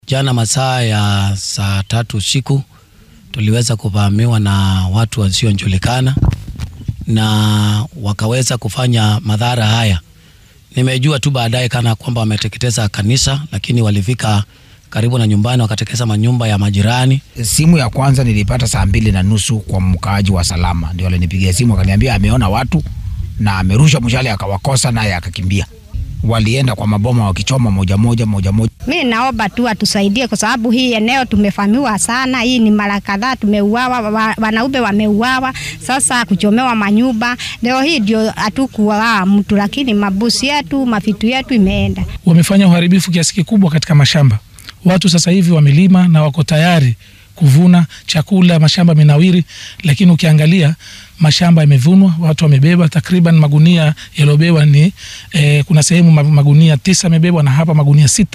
Waxaa weerarkan ka hadlay dadka deegaanka oo ay waxyeeladu soo gaartay